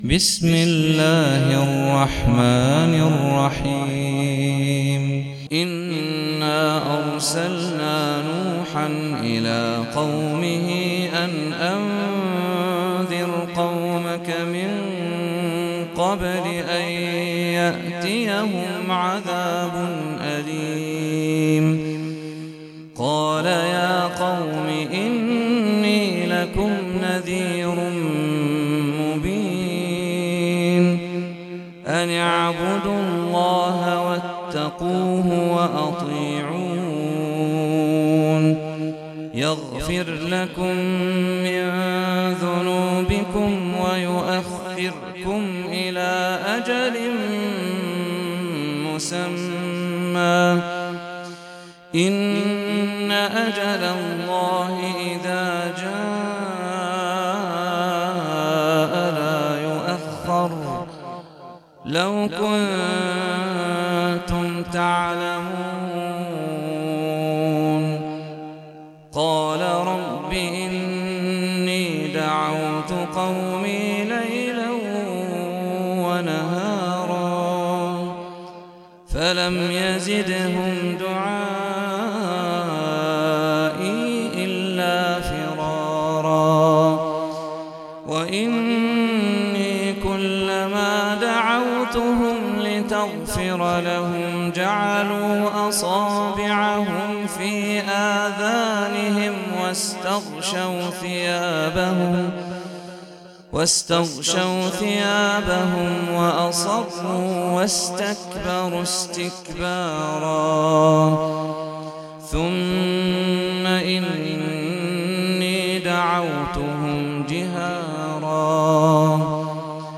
سورة نوح - صلاة التراويح 1446 هـ (برواية حفص عن عاصم)